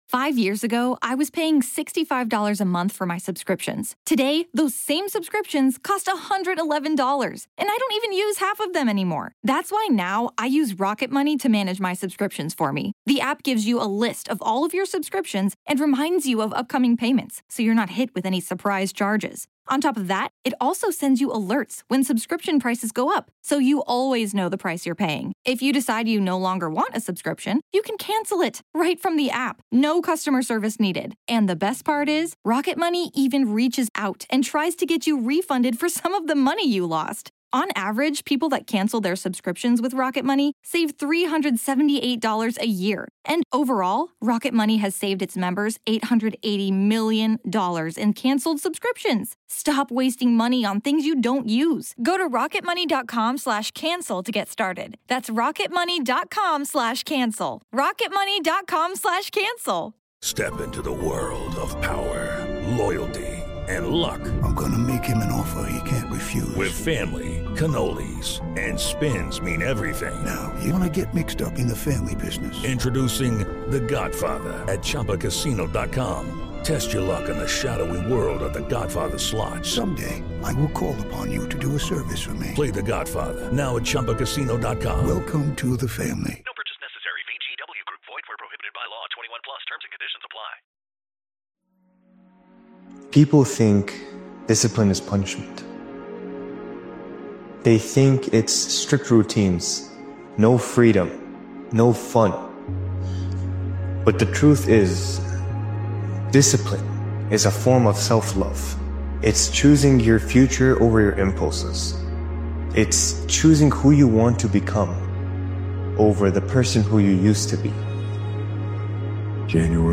This powerful motivational speech compilation is about drawing a hard line between the past and what comes next. 2026 isn’t about repeating old habits or carrying old limits forward—it’s about committing to real change with clarity and intent.